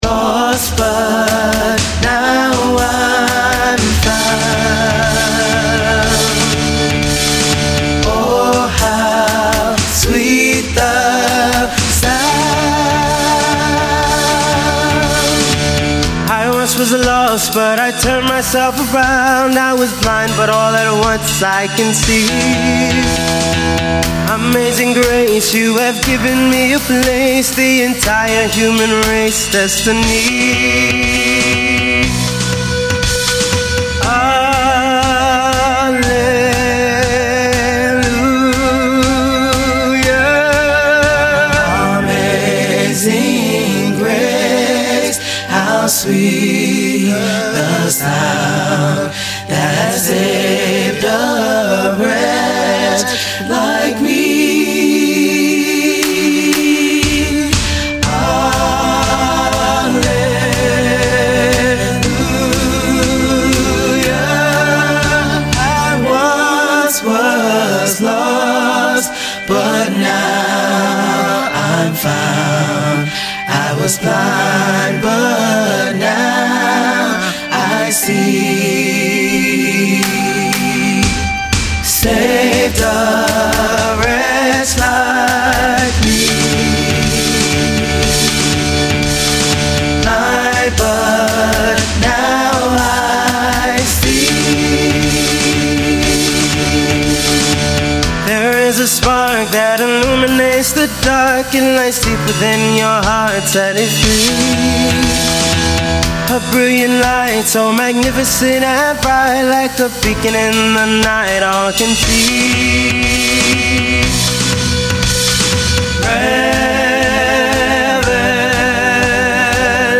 Sung by a choir, with instrumental accompaniment